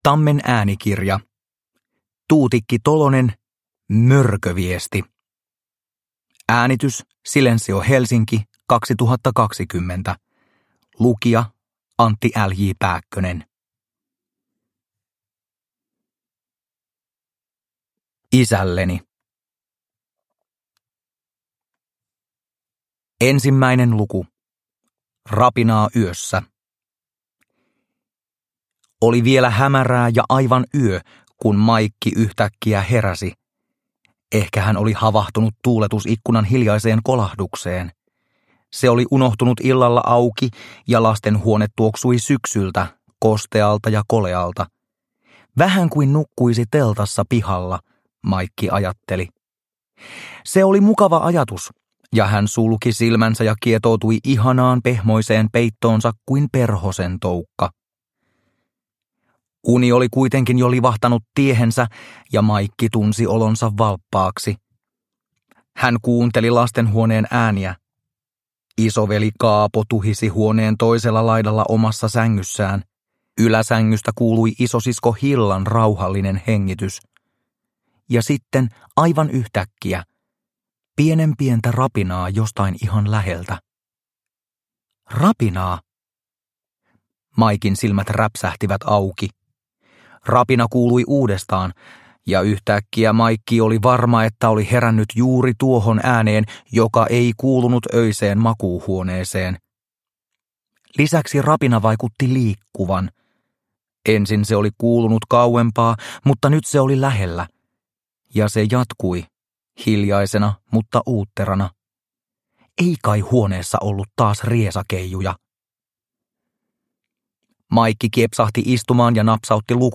Produkttyp: Digitala böcker
Uppläsare: Antti L. J. Pääkkönen